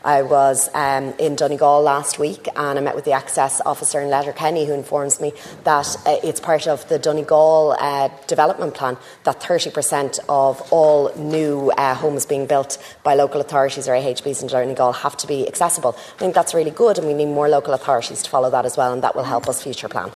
Minister of State with special responsibility for Disability Emer Higgins, was taking questions this morning in the chamber.
emer-higgins-5pm.mp3